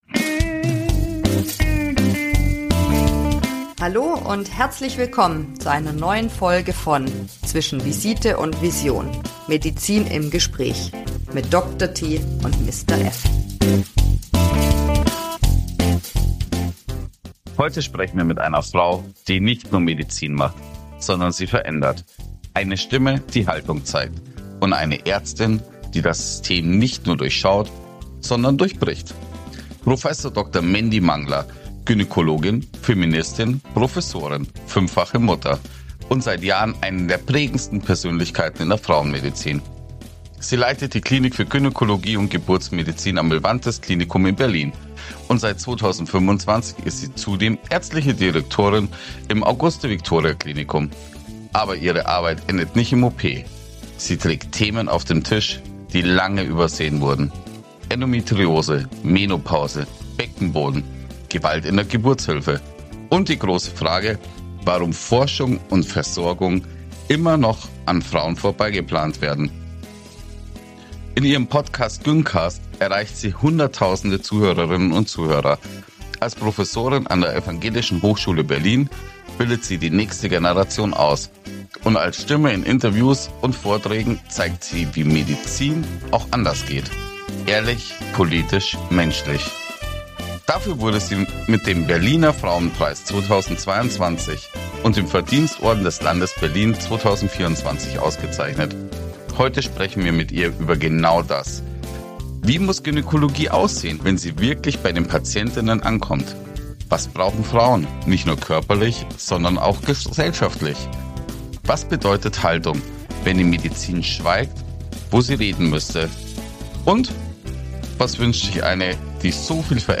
In dieser Folge sprechen wir mit ihr über die großen Themen der Frauenmedizin: Versorgungslücken, Aufklärung, Forschungslücken – und darüber, warum Haltung in der Medizin wichtiger denn je ist. Ein Gespräch über Geburtshilfe, Gerechtigkeit und eine Medizin, die endlich zuhört.